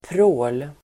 Uttal: [prå:l]